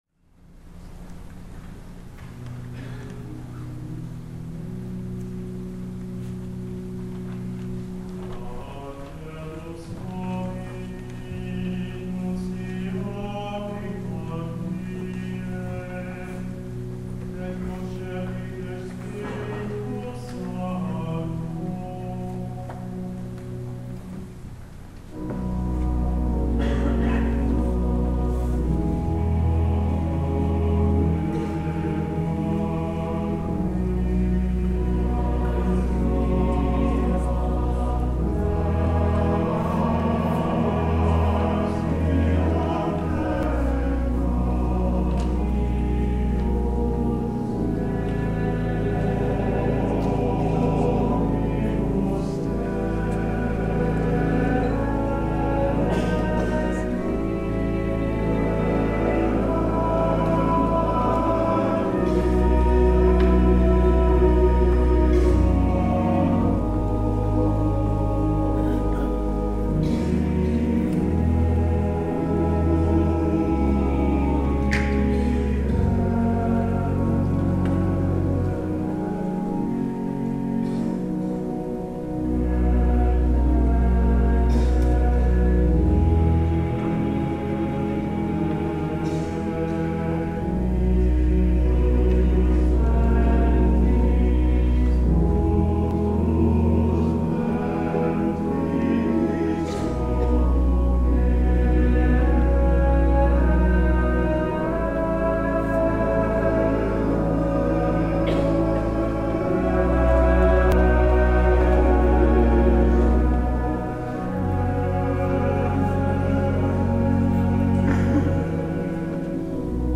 CANDLELIGHT SERVICE
PRELUDE
Men's Ensemble